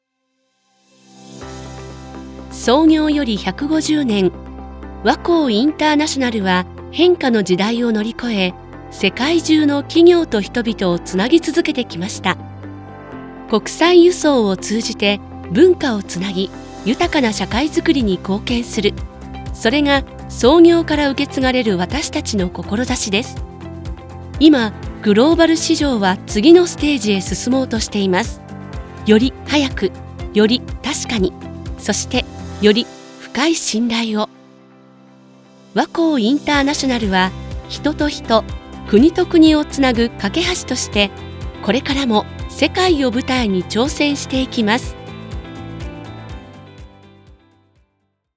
落ち着いた声で
信頼感や誠実さが伝わる
ナレーションを
しっかりした